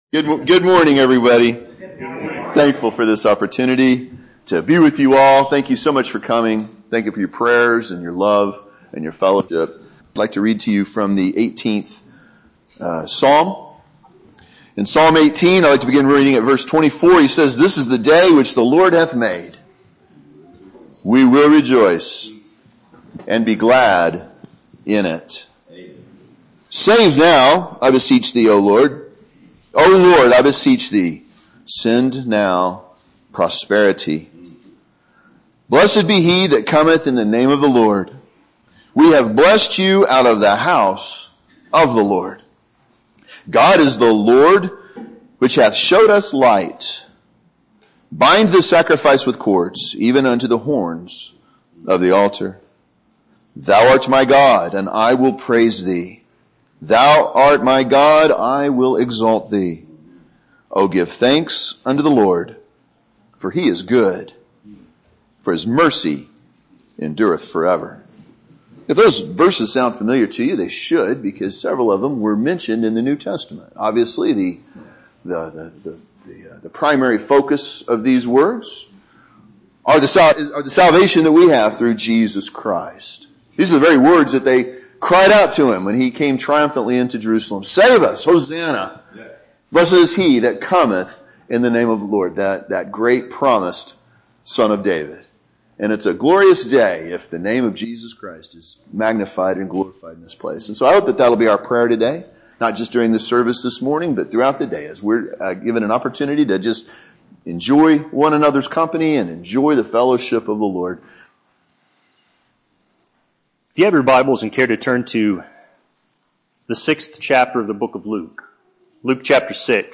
Sermons preached in other churches • Page 24
This sermon was recorded at Walnut Valley Primitive Baptist Church Located in Blanchard, Oklahoma